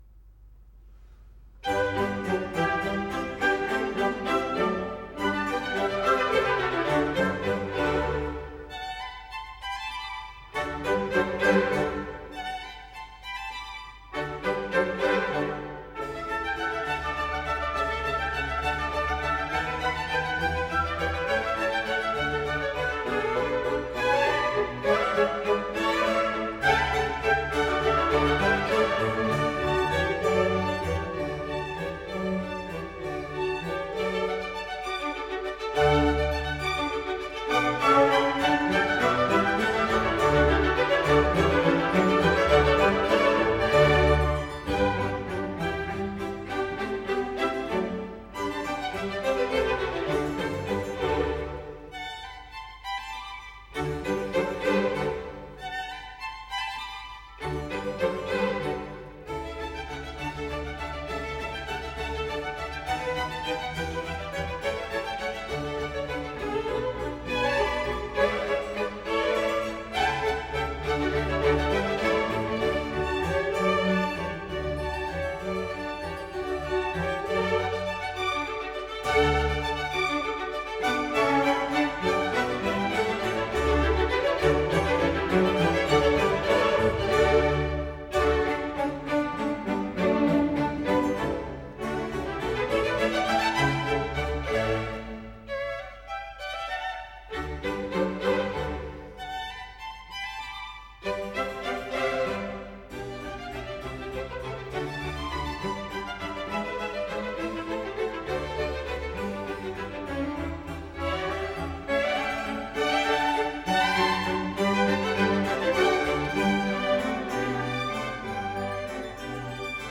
in G major - Allegro